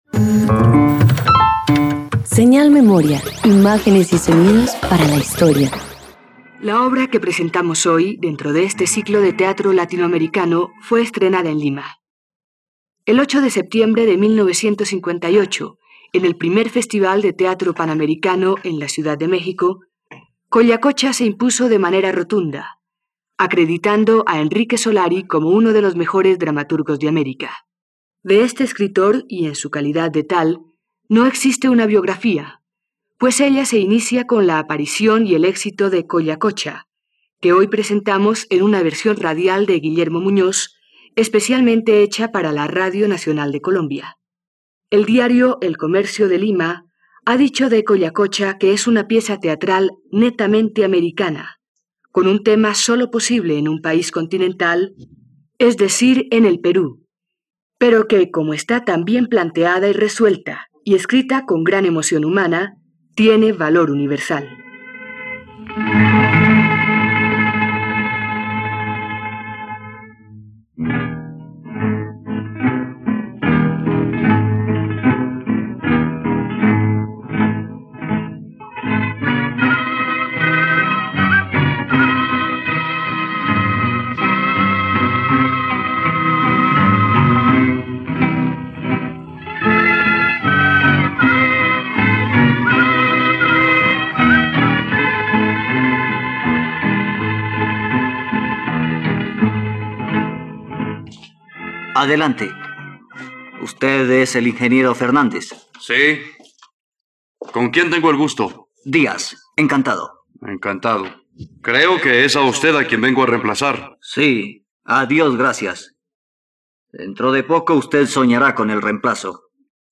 Collacocha - Radioteatro dominical | RTVCPlay
..Radioteatro. Escucha la adaptación radiofónica de “Collacocha” de Enrique Solari por la plataforma streaming RTVCPlay.